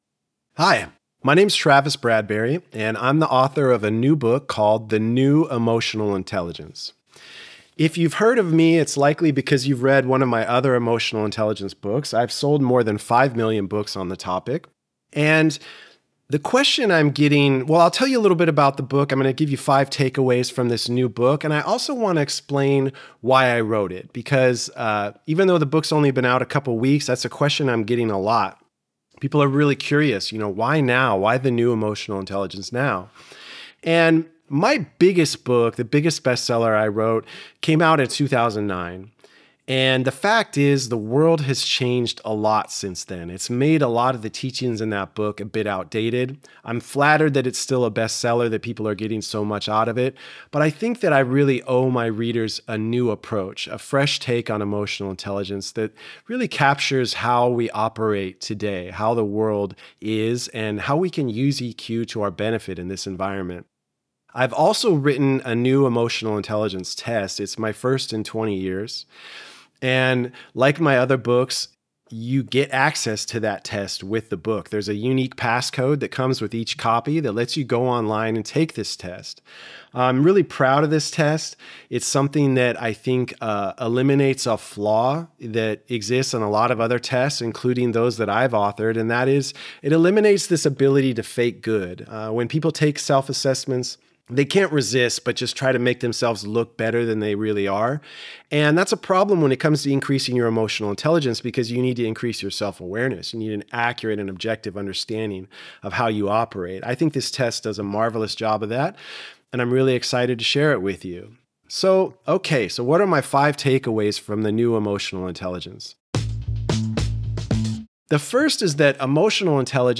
Listen to the audio version—read by Travis himself—in the Next Big Idea App.